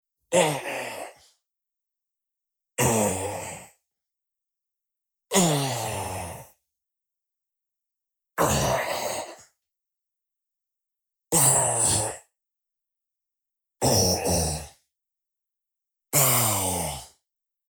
Primero recorto sutilmente las colas de la pista principal con un Fade Out para que en la cola del grito suene con más aire.
Para ello elevo el brillo y el grave de la pista, aplicando muchísima compresión en estas bandas, para que no se excedan.
Así suena la mezcla de las 2 pistas.